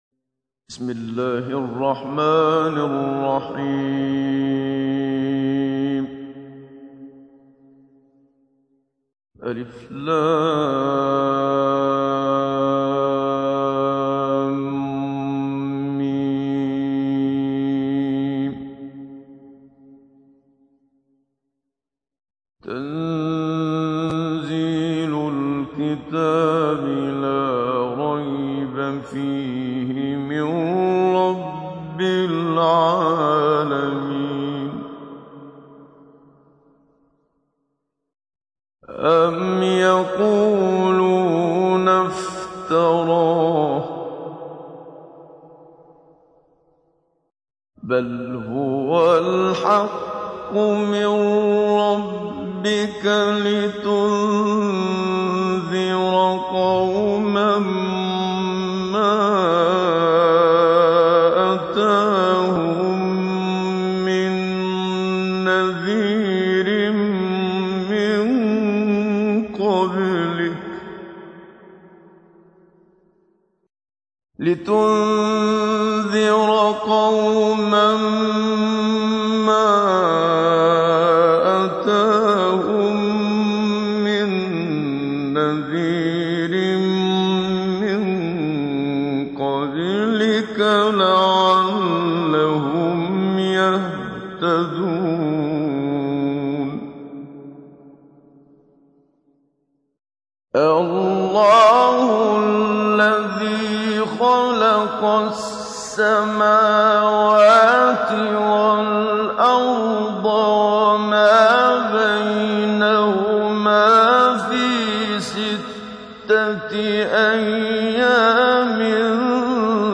تحميل : 32. سورة السجدة / القارئ محمد صديق المنشاوي / القرآن الكريم / موقع يا حسين